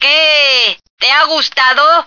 flak_m/sounds/female1/est/F1likethat.ogg at d2951cfe0d58603f9d9882e37cb0743b81605df2